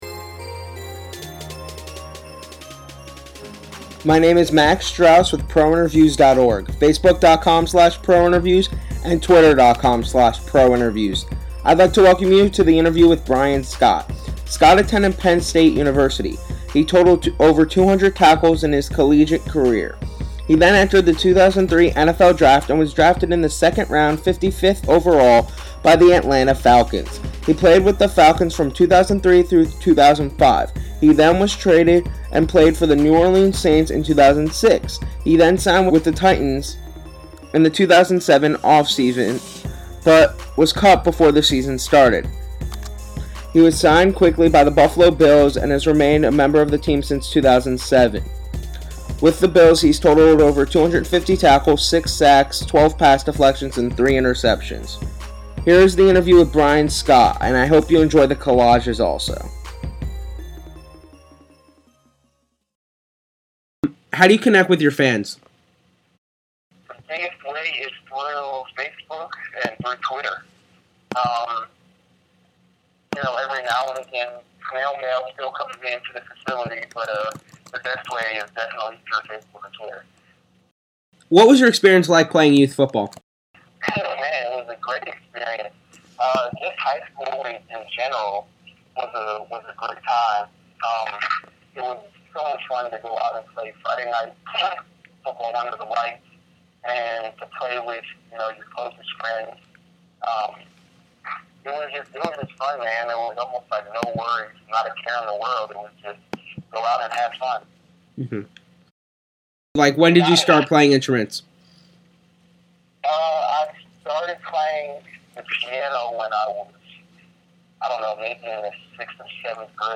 Bills S, Bryan Scott Interview